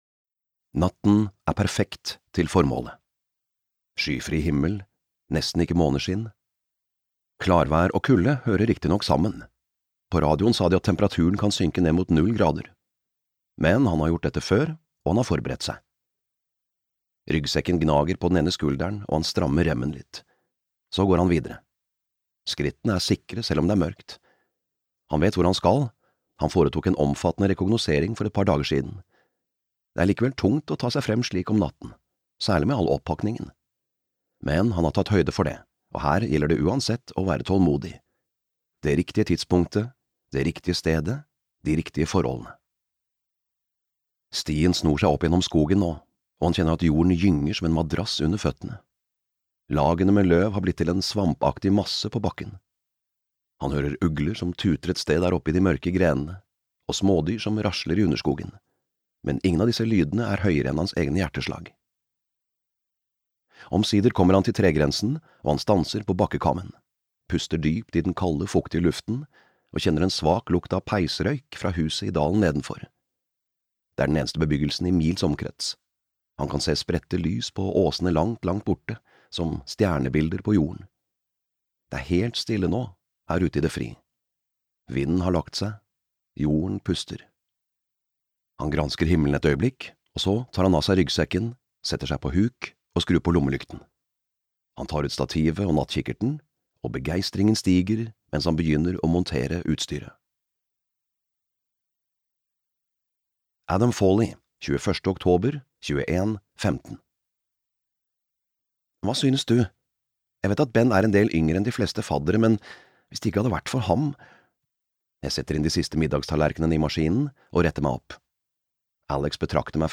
Den som hvisker lyver (lydbok) av Cara Hunter